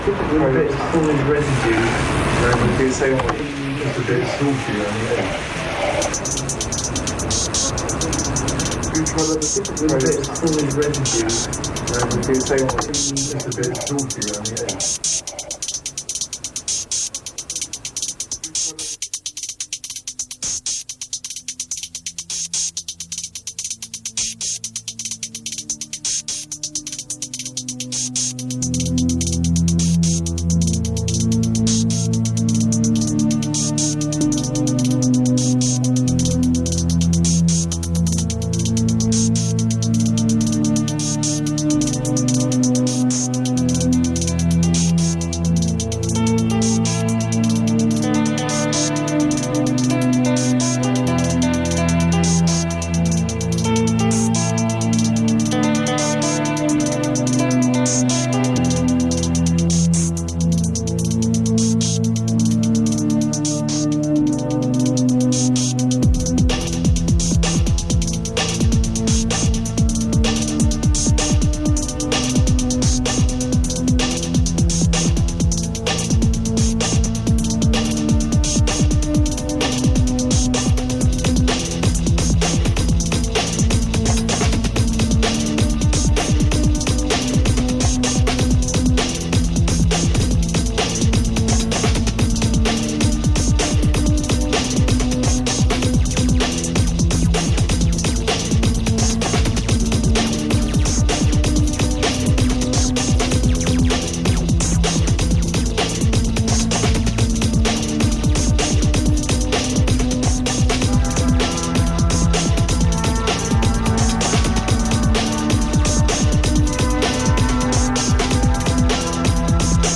location Australia, Melbourne venue Flemington Racecourse